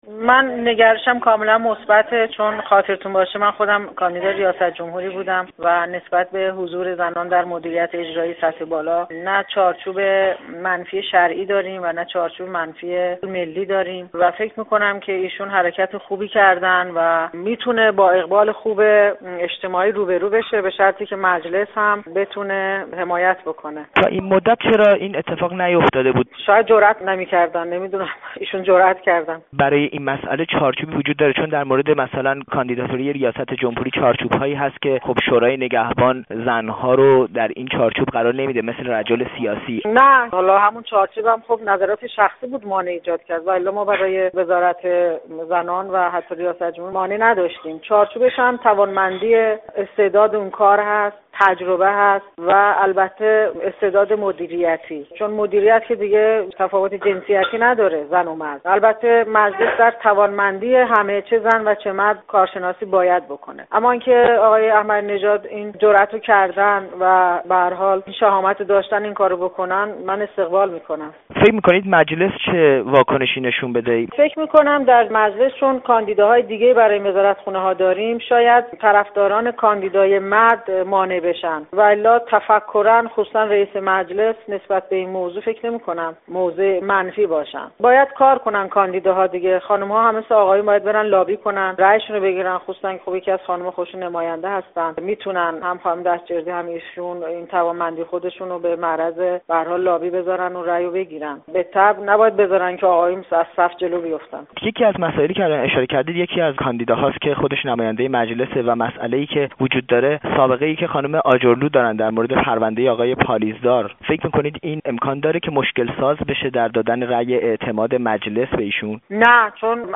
گفت‌وگو با رفعت بیات؛ نماینده پیشین مجلس ایران